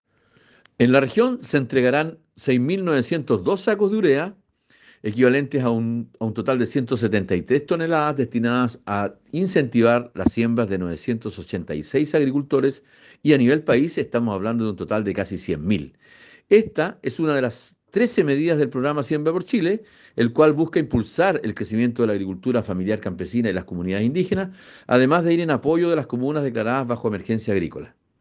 El seremi de agricultura, Patricio Barría, se refirió a las cifras que contempla esta iniciativa a nivel regional y nacional: